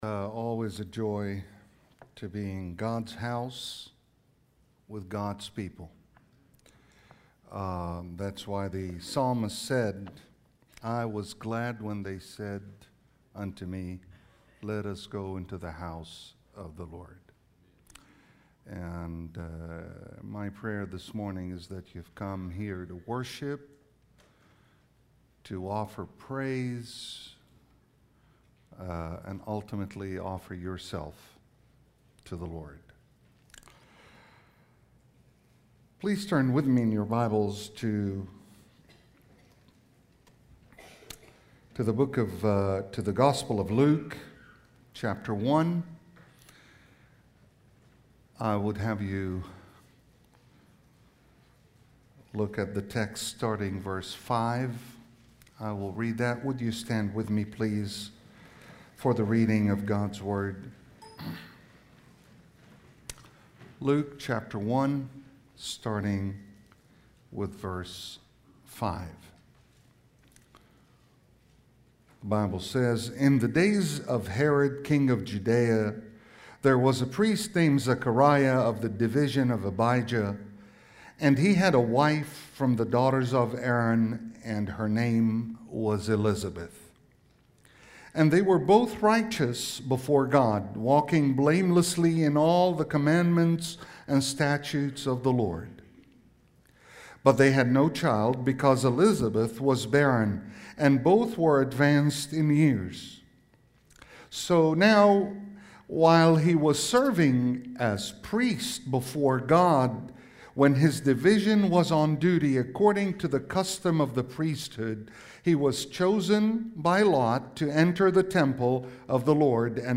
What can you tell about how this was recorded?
(Morning Worship)